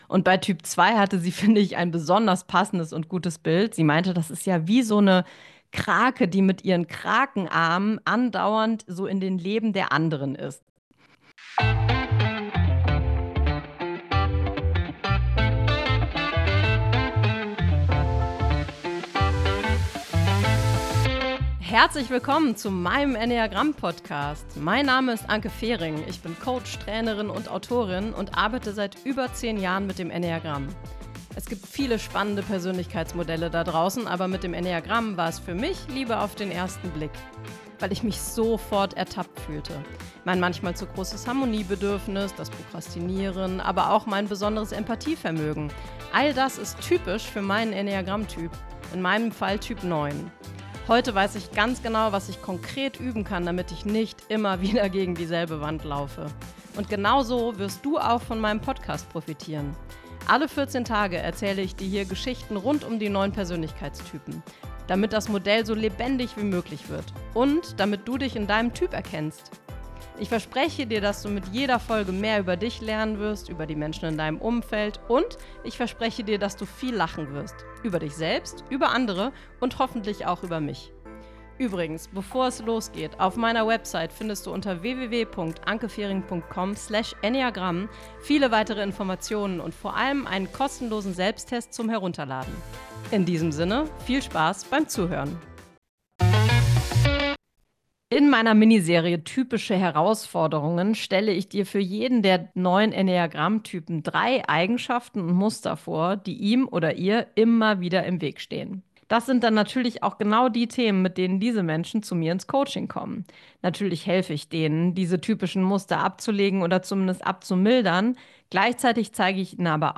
Solofolge_Herausforderungen_Typ2.mp3